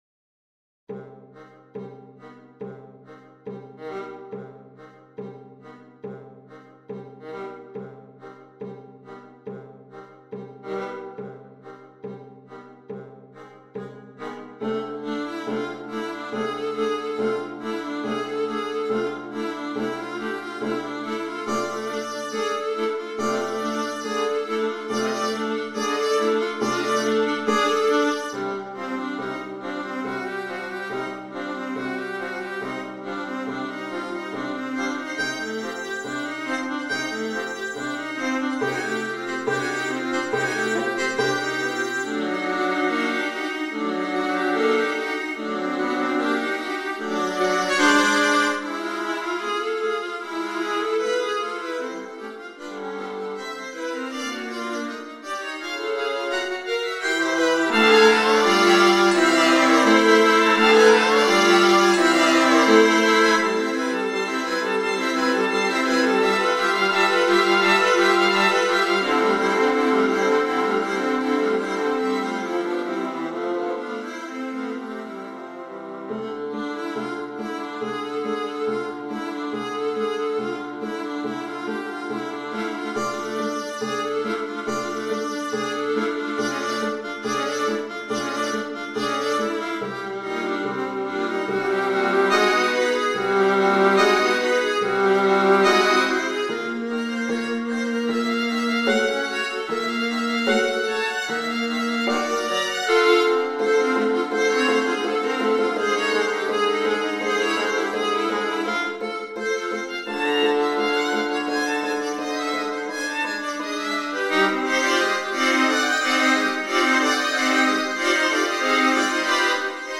It makes for a very exciting viola quartet.